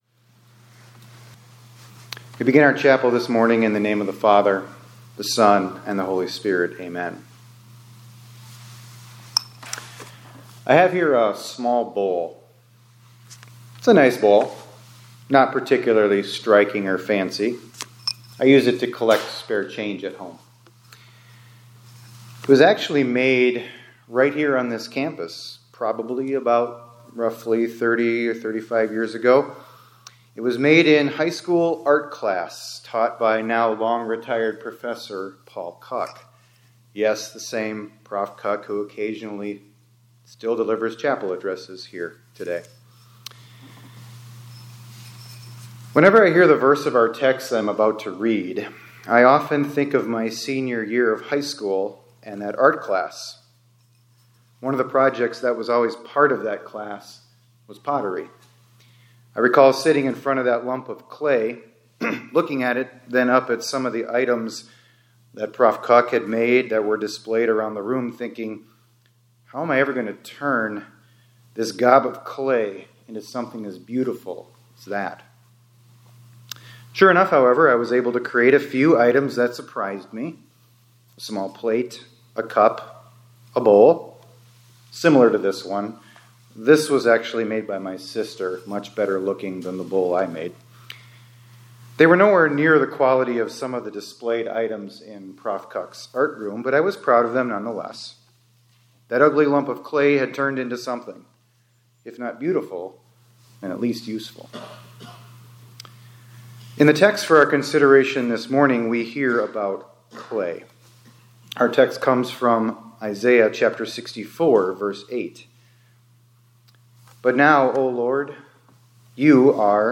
2026-01-20 ILC Chapel — The Potter Has a Plan For You